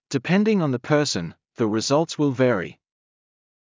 ﾃﾞｨﾍﾟﾝﾃﾞｨﾝｸﾞ ｵﾝ ｻﾞ ﾊﾟｰｿﾝ ｻﾞ ﾘｿﾞﾙﾂ ｳｨﾙ ﾊﾞﾘｰ